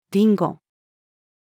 リンゴ-female.mp3